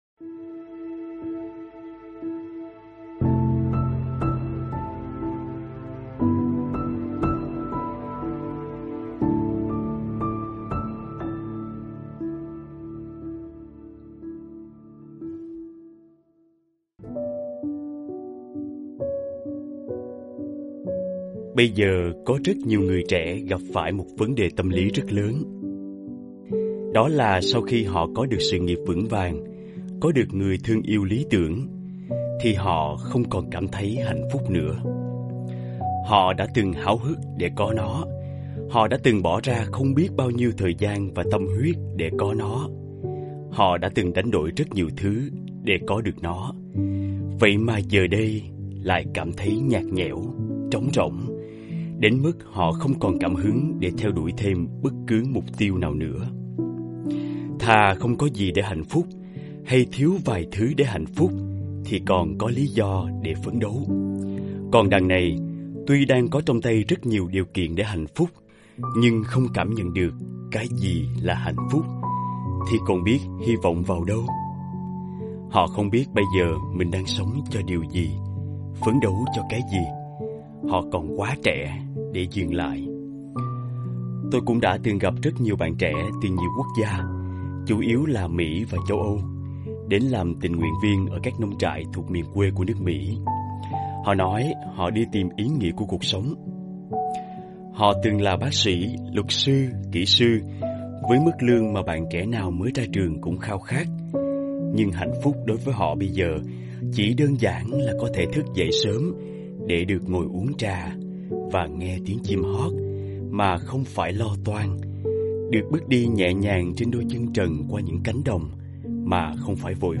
Sách nói mp3